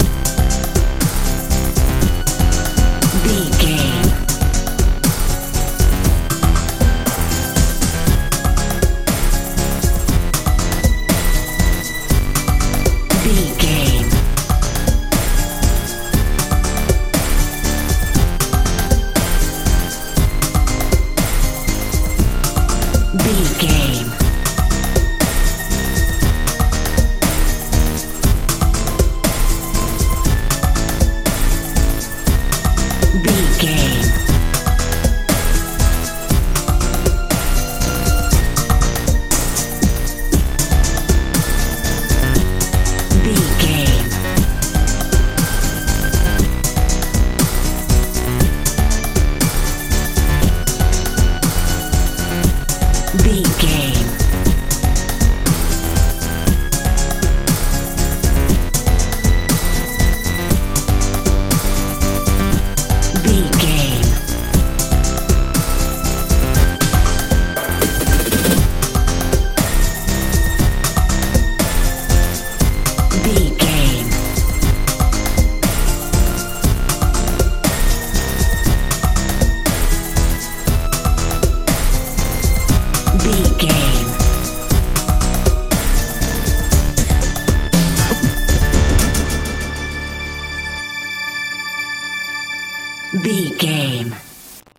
modern dance feel
Ionian/Major
magical
mystical
synthesiser
bass guitar
drums
suspense
strange